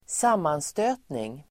Uttal: [²s'am:anstö:tning]